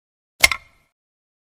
after wooden-button-click-sound-effect.mp3